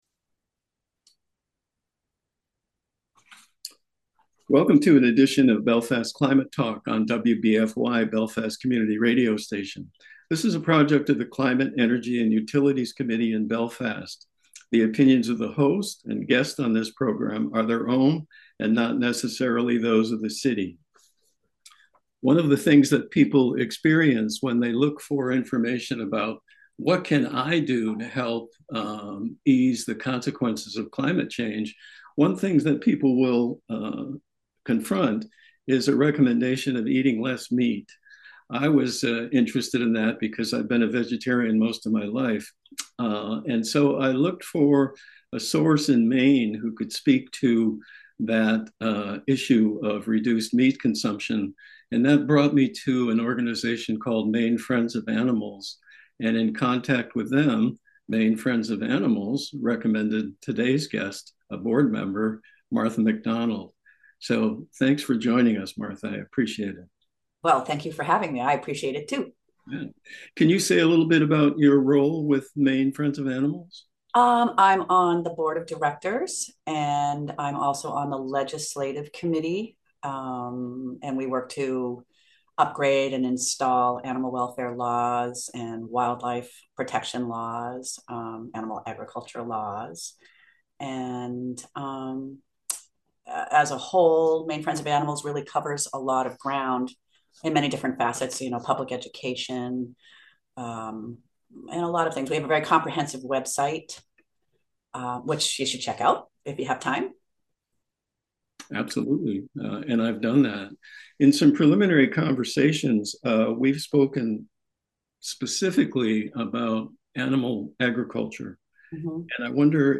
INTERVIEW PREFACE
radio-interview.mp3